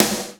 RS SNR 1  -R.wav